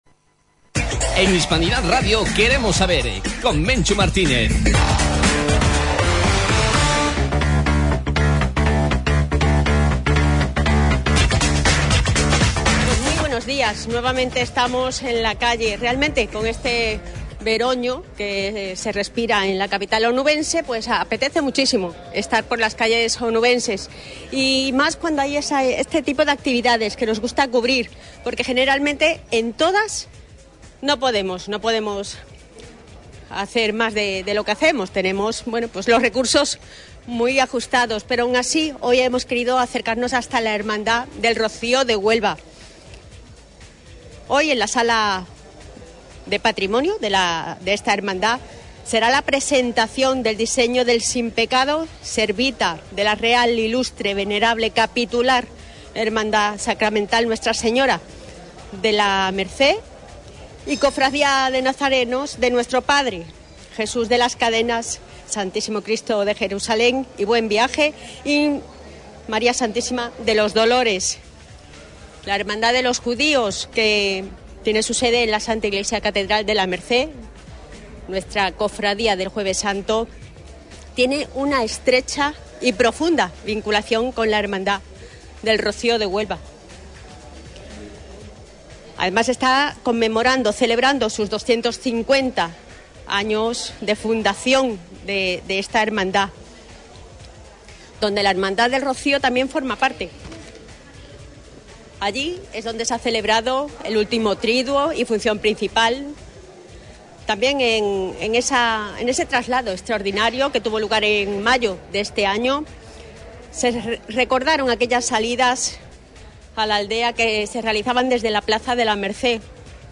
Hoy estuvimos en directo desde la Casa de Hermandad del Rocío de Huelva, donde tuvo lugar la presentación del diseño del Simpecado Servita de la Real, Ilustre, Venerable y Capitular Hermandad Sacramental Nuestra Señora de la Merced y Cofradía de Nazarenos de Nuestro Padre Jesús de las Cadenas, Stmo. Cristo de Jerusalén y Buen Viaje y María Stma. de los Dolores, con sede en la S. I. C. la [...]